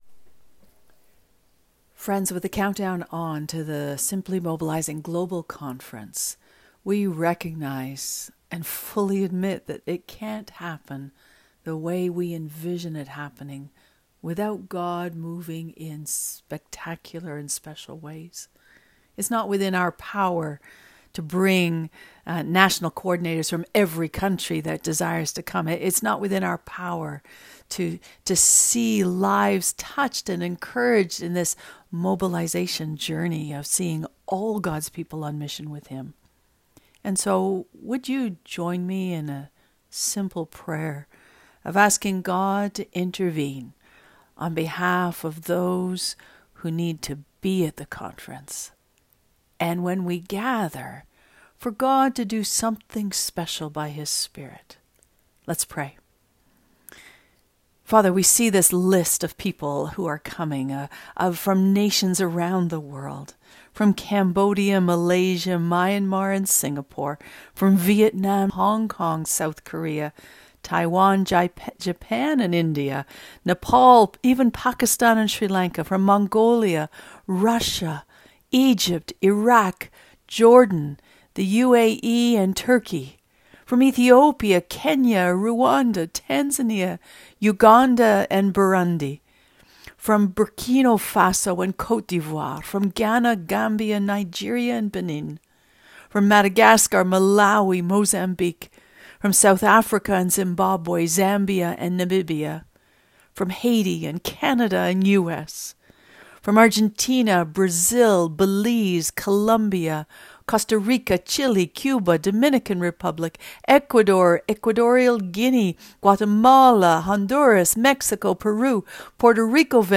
Prayer For International Conference